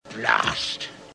Play, download and share Blast Munchausen original sound button!!!!
blast_rFbPFaf.mp3